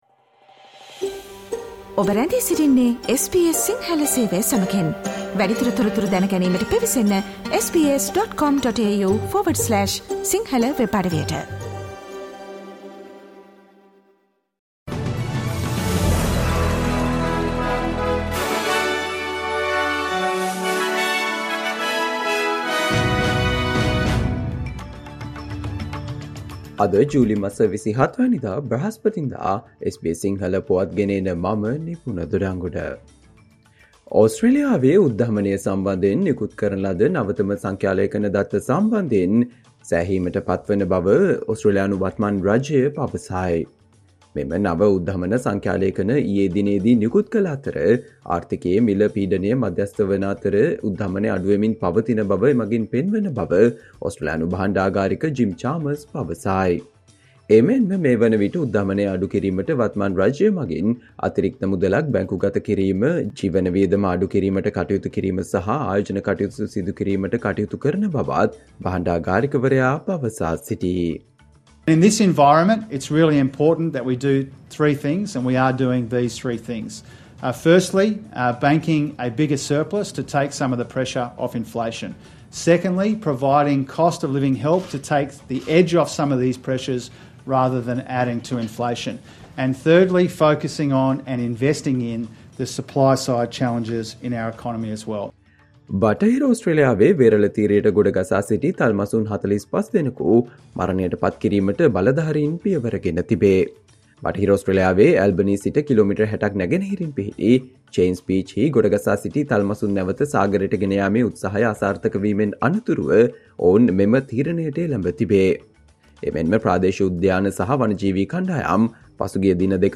Australia news in Sinhala, foreign and sports news in brief - listen, today - Thursday 27 July 2023 SBS Radio News